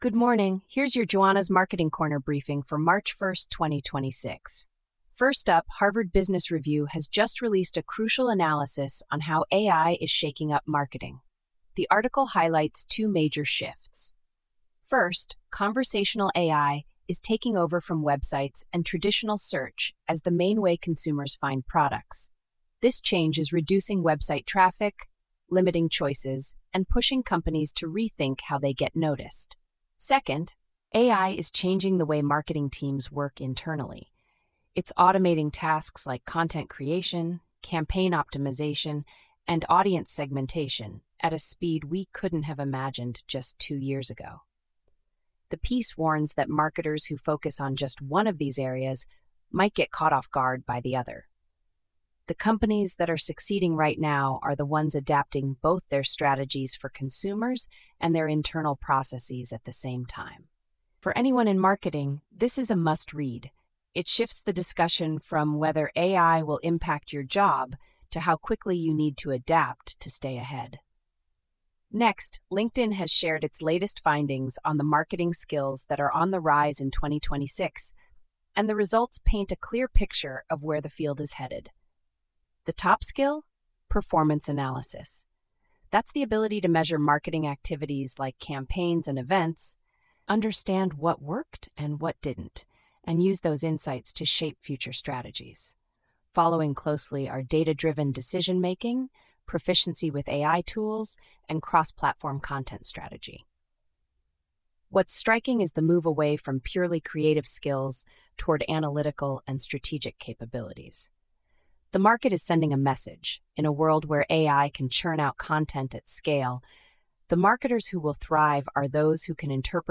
Your daily curated news briefing!